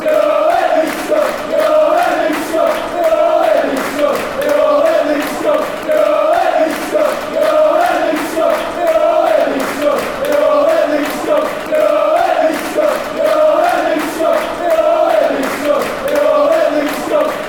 Listen to 71 Accrington Stanley FC football songs and Accrington soccer chants from Fraser Eagle Stadium.